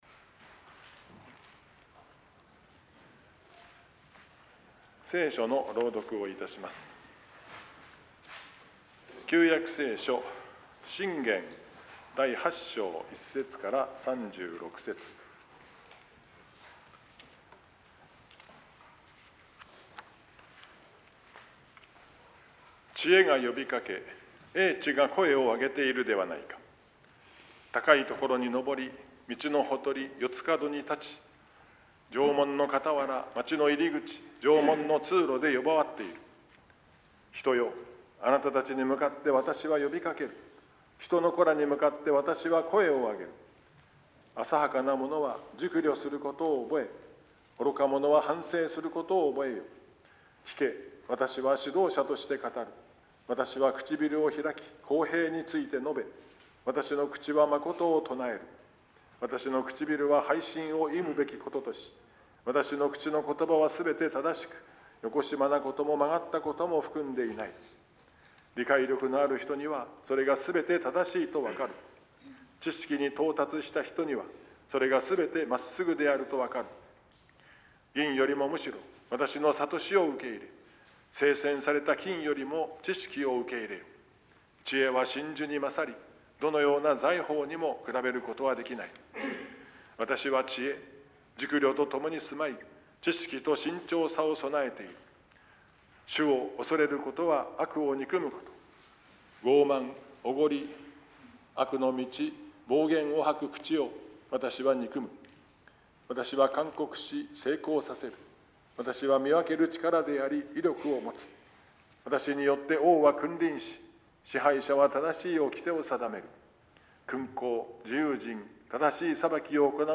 説 教